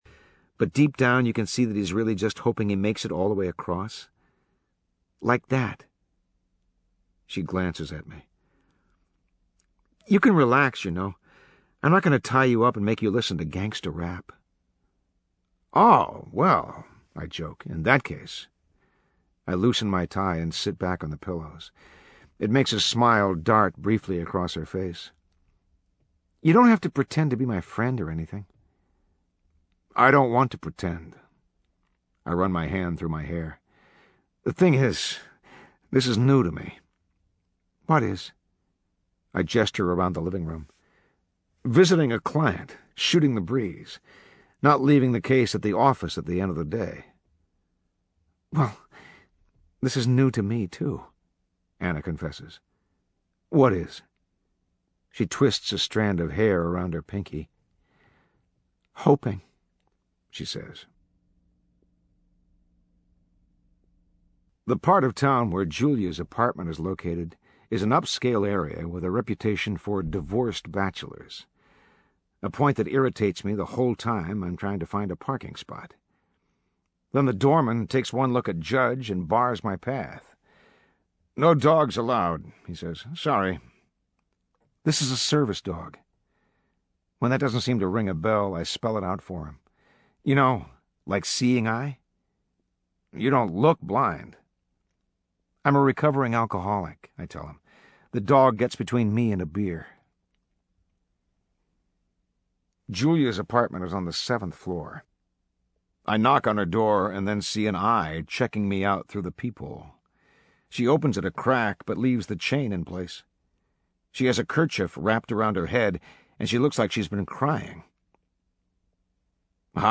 英文广播剧在线听 My Sister's Keeper（姐姐的守护者）52 听力文件下载—在线英语听力室